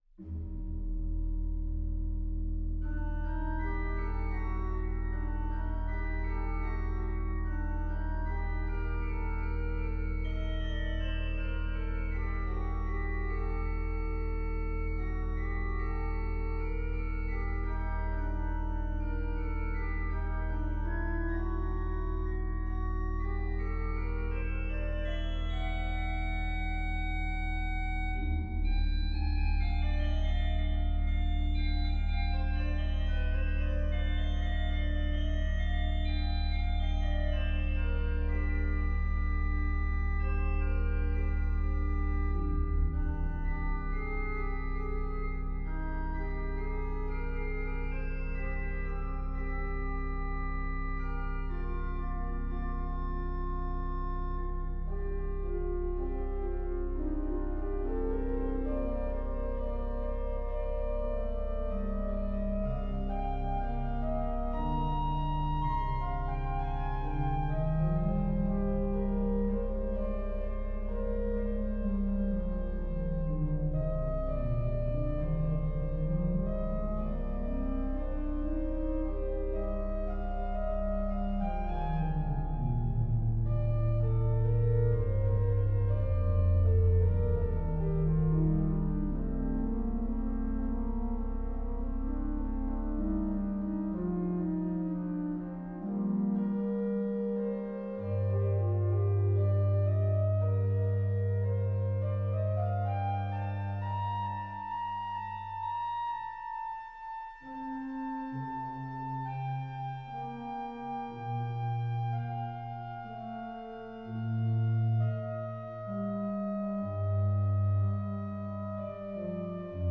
organ Duration